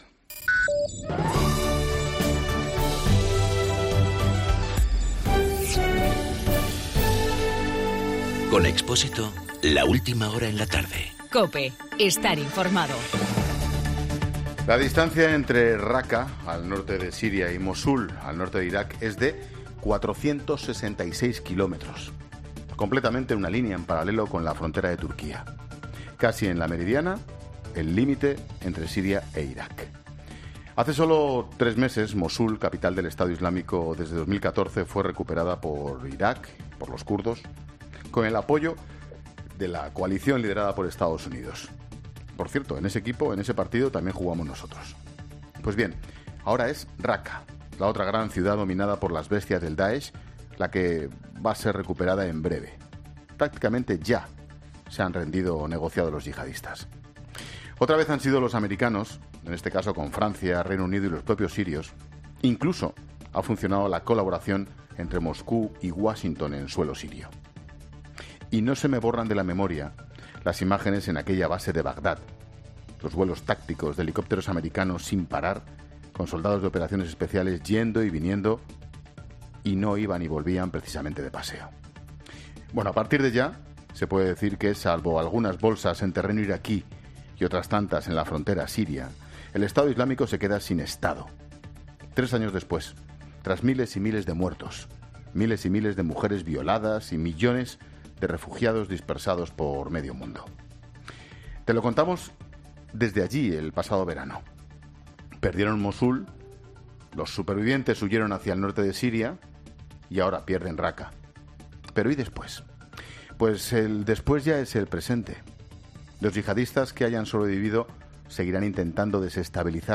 AUDIO: Comentario de Ángel Expósito.